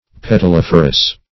Petaliferous \Pet`al*if"er*ous\
petaliferous.mp3